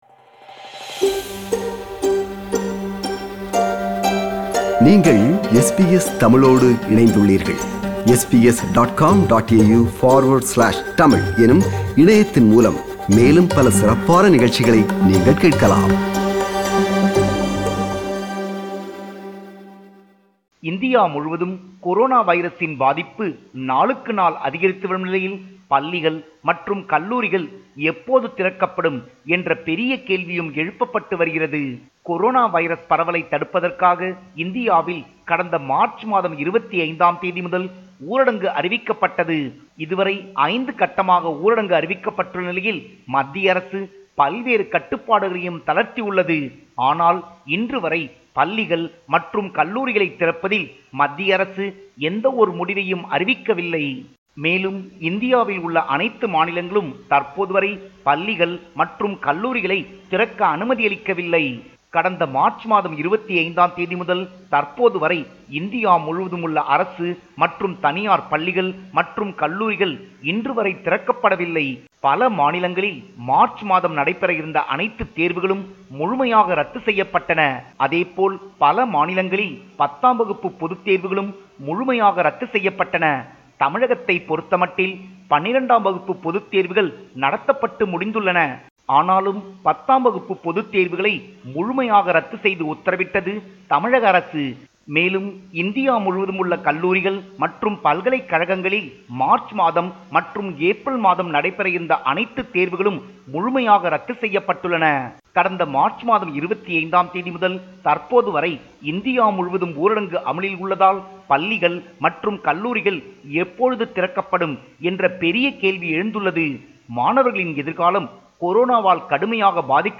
our correspondent in India, compiled a report focusing on major events/news in Tamil Nadu / India.